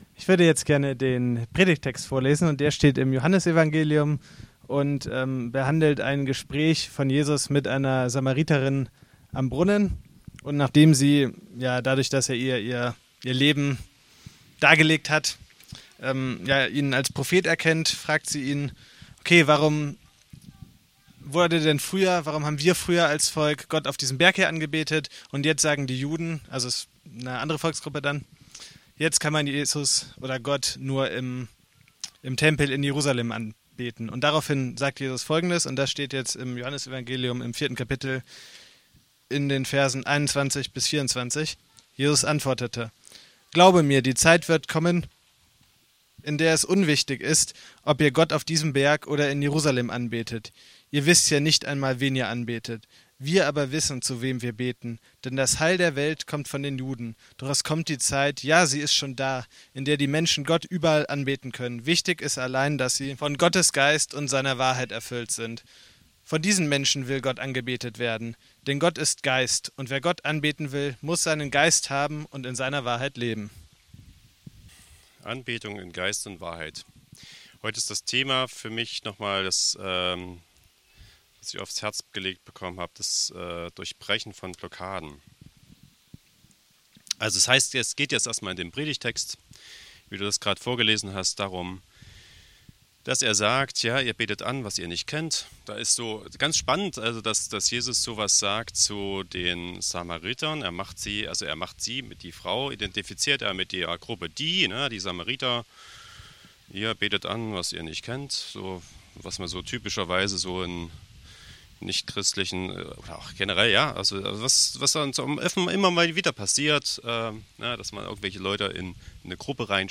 Predigt: „In Geist und Wahrheit.